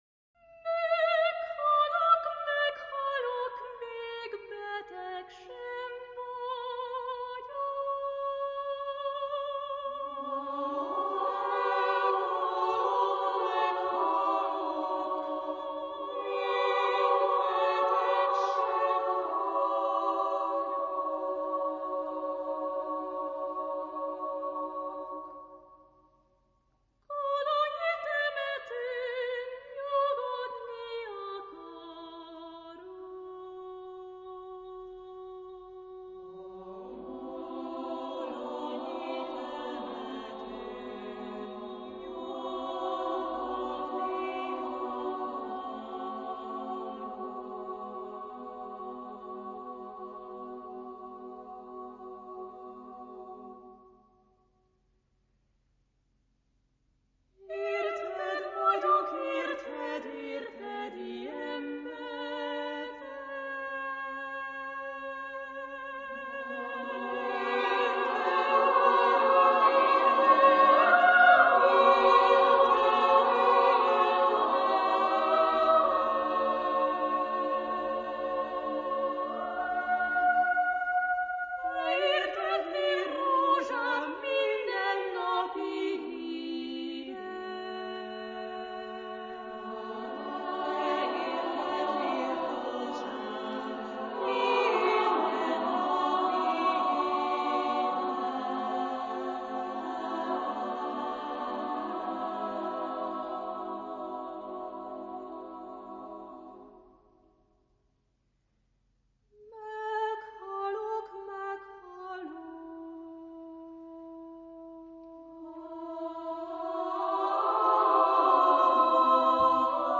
Genre-Style-Form: Sacred ; Folk music
Type of Choir: SSAA (div)  (4 women voices )
Soloist(s): Sopranos (2) / Altos (2)  (4 soloist(s))
Tonality: C sharp minor